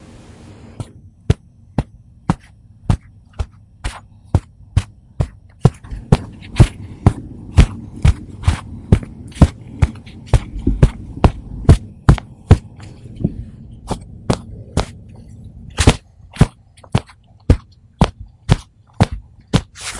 脚踩在地毯上
描述：走在地毯上。用我的ZOOM H2N记录。
标签： 地毯 脚步声 步骤 行走 脚步
声道立体声